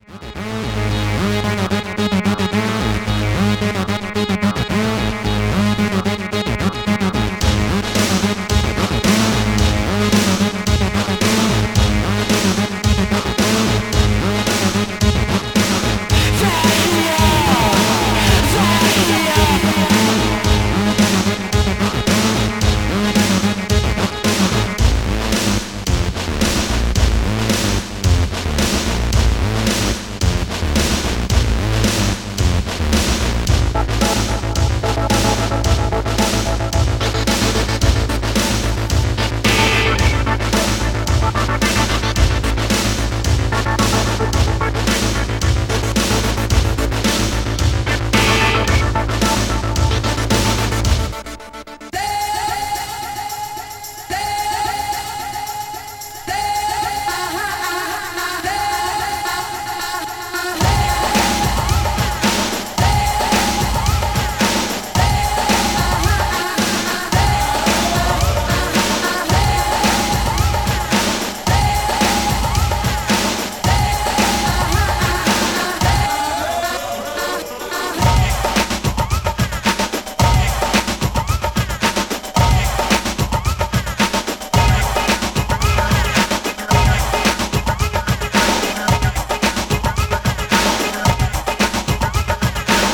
STYLE Breakbeat